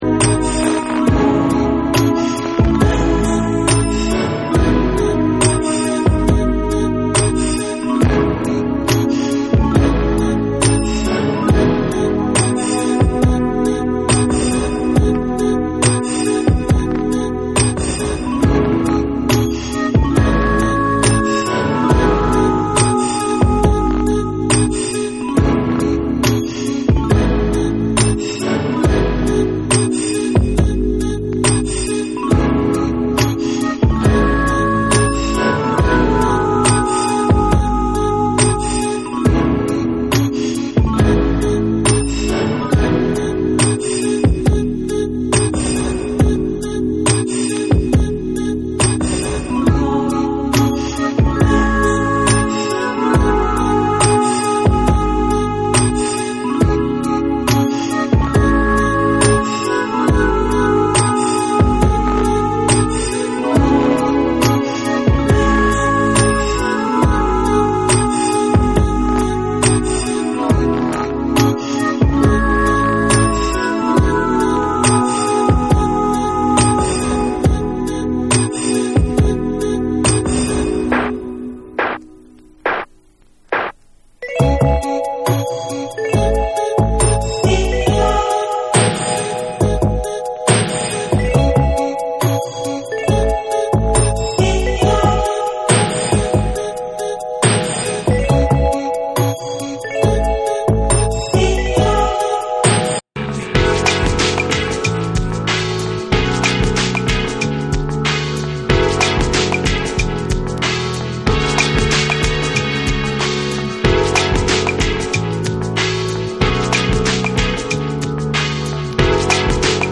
極美なメロディーが夕暮れにハマりそうなチルアウト・ナンバーを多数収録したオススメ・コンピレーション！
BREAKBEATS / CHILL OUT